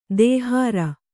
♪ dēhāra